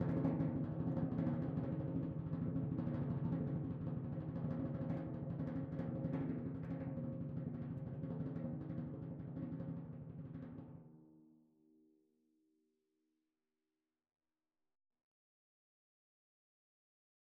Timpani4_Roll_v3_rr1_Sum.wav